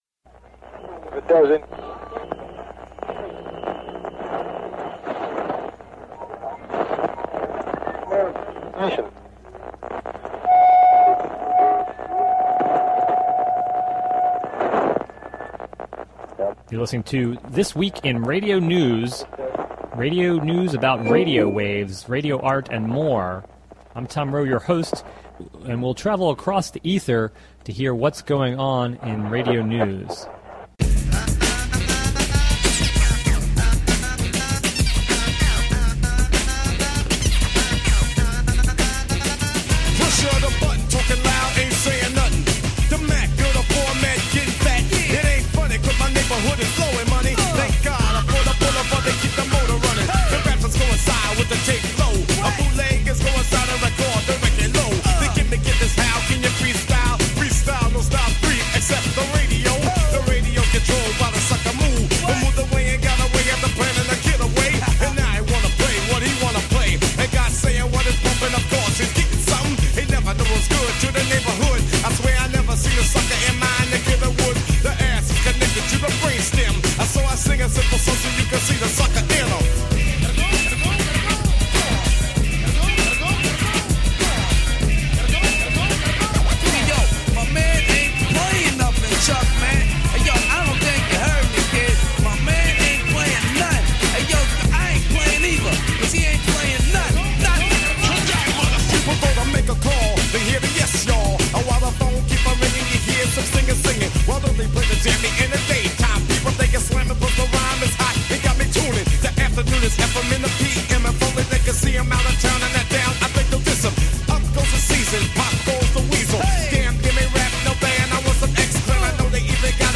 Weekly summary of radio news about radio waves with reports about the few female country music stars you hear on the radio; Stingrays getting cell phone info without a warrant; 'Amateur Radio Newsline' off the air; Charter attempts to buy Time Warner.